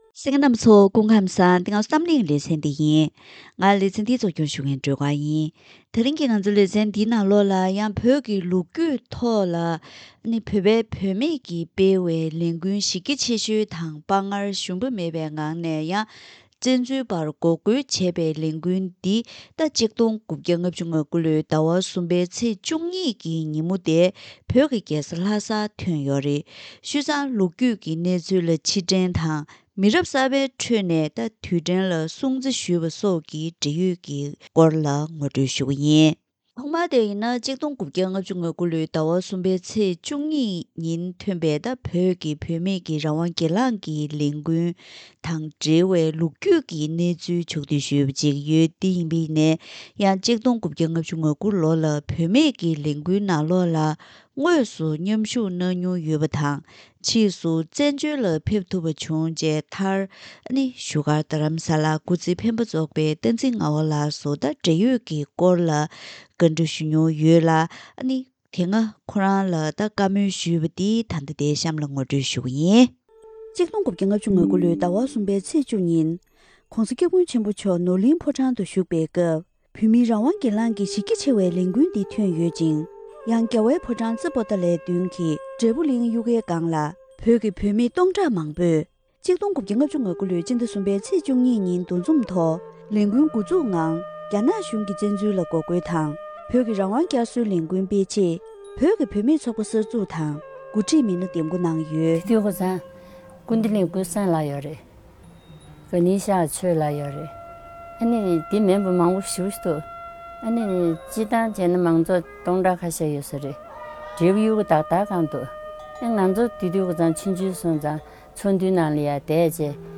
ད་རིང་གི་གཏམ་གླེང་ལེ་ཚན་འདིའི་ནང་བོད་ཀྱི་ལོ་རྒྱུས་ཐོག་བོད་པའི་བུད་མེད་ཀྱིས་སྤེལ་བའི་ལས་འགུལ་གཞི་རྒྱ་ཆེ་ཤོས་དང་དཔའ་ངར་ཞུམ་མེད་ངང་བཙན་འཛུལ་པར་འགོག་རྒོལ་བྱས་པའི་ལས་འགུལ་འདི་༡༩༥༩ལོའི་ཟླ་༣ཚེས་༡༢ཉིན་བོད་ཀྱི་རྒྱལ་ས་ལྷ་སར་ཐོན་ཡོད་པས། ལོ་རྒྱུས་ཀྱི་གནས་ཚུལ་ལ་ཕྱིར་དྲན་དང་། མི་རབས་གསར་པའི་ཁྲོད་ནས་དུས་དྲན་ལ་སྲུང་བརྩི་ཞུས་པ་སོགས་ཀྱི་འབྲེལ་ཡོད་སྐོར་ངོ་སྤྲོད་ཞུ་རྒྱུ་ཡིན།